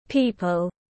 Mọi người tiếng anh gọi là people, phiên âm tiếng anh đọc là /ˈpiː.pəl/.
People /ˈpiː.pəl/